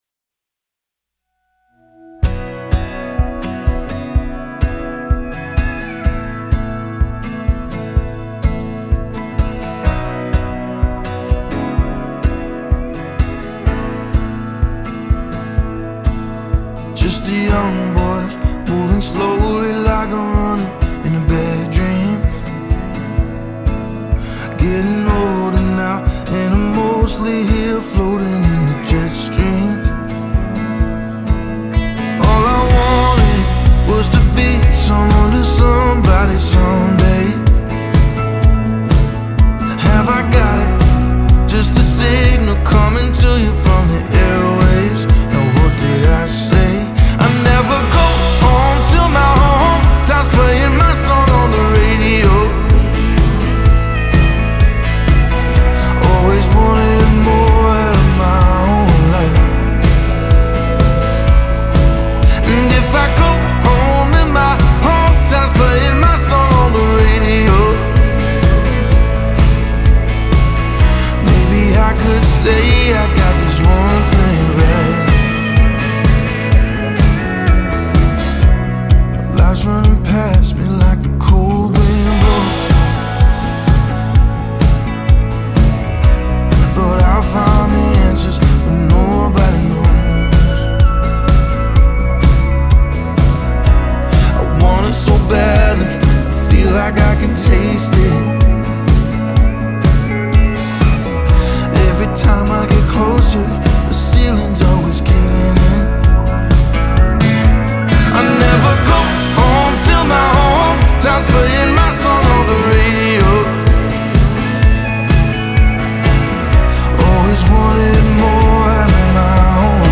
Part crooner, part troubadour, all storyteller.